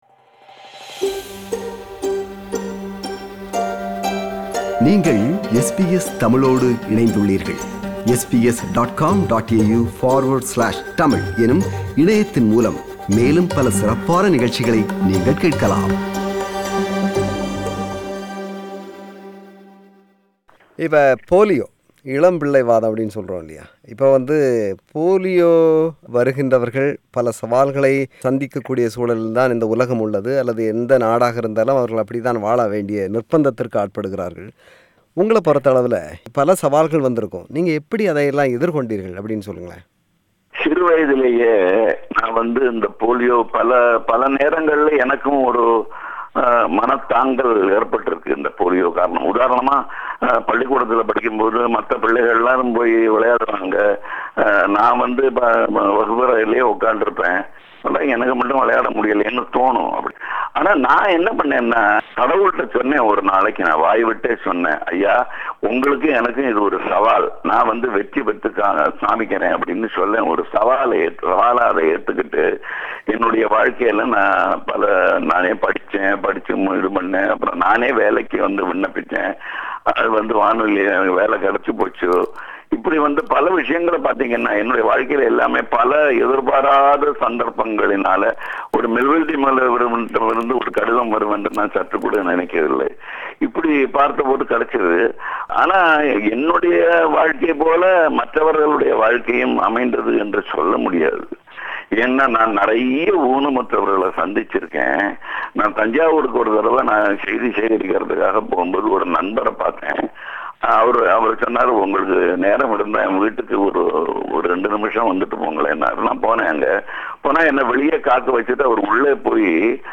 நேர்முகம் பாகம் 2